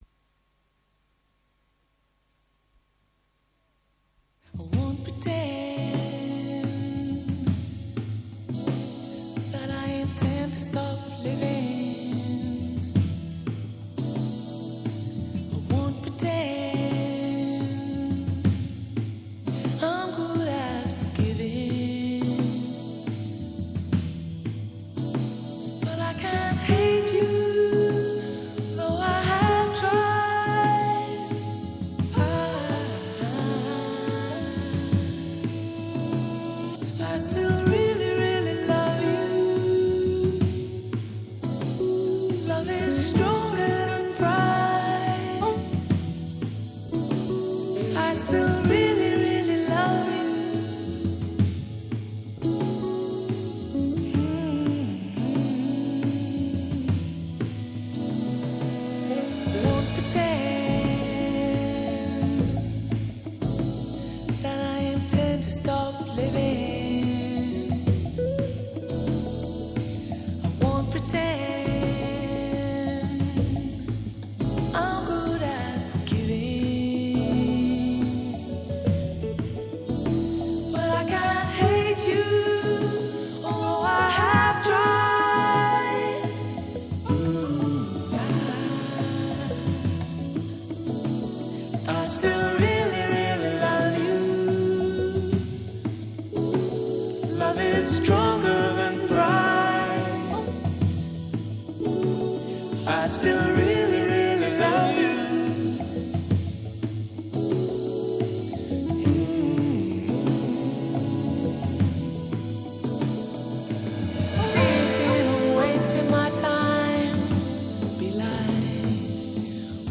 R&B, Soul